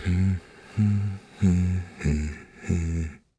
Riheet-Vox_Hum.wav